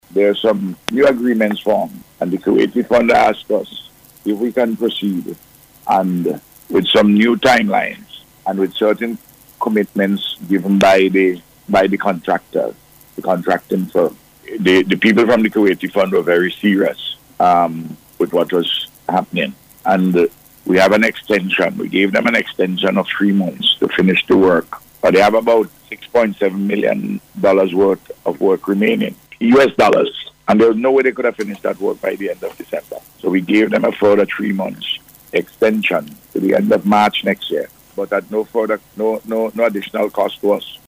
Speaking on NBC’s Face to Face programme this morning, the Prime Minister said the project has encountered delays, because of difficulties with the Contractor.